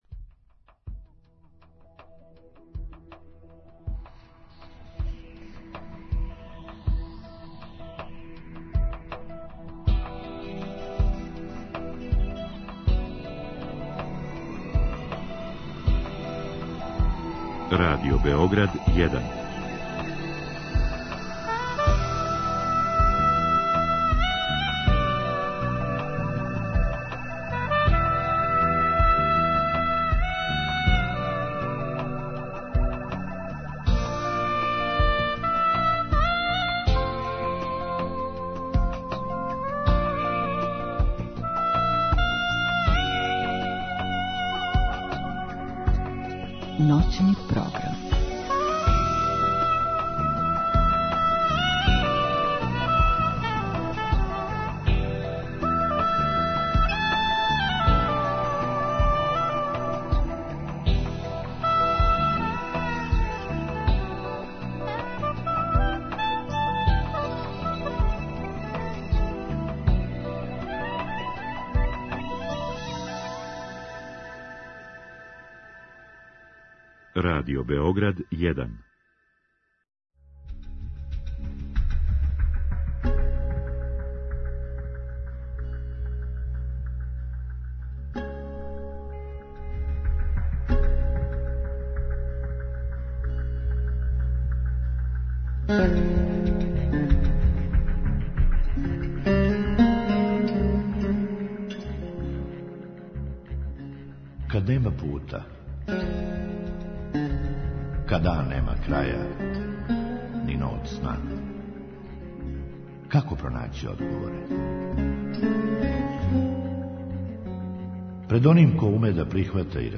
Да ли се и како припремају деца за улазак у хранитељску породицу? Како се врши контрола хранитеља и спречавају злоупотребе? У другом сату емисије позивамо наше слушаоце који су вољни да поделе са нама своје утиске, искуства, предлоге, проблеме и радости.